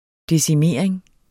Udtale [ desiˈmeˀɐ̯eŋ ]